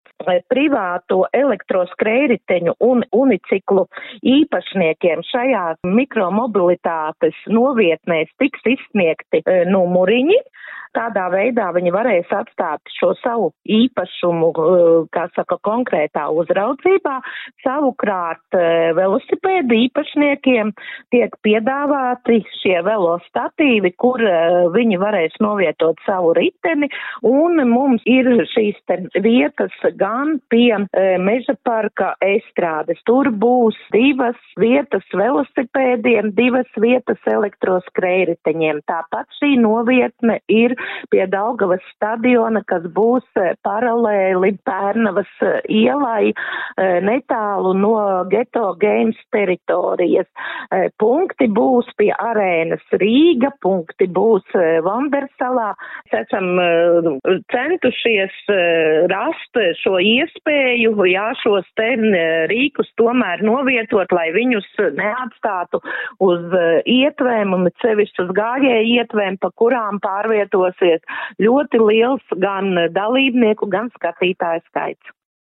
RADIO SKONTO Ziņās par mikromobilitātes novietnēm